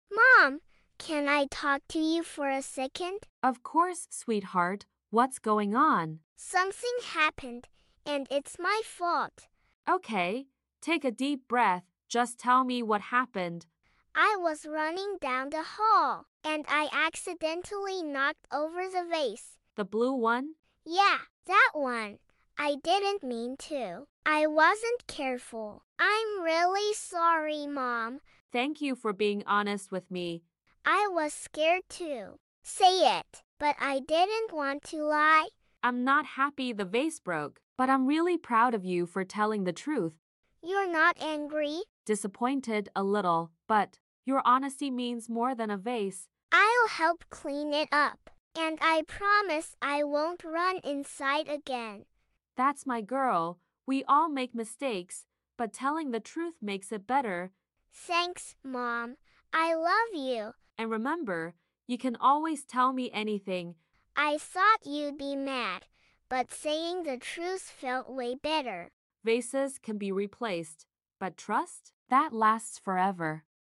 speaking practice through dialogue.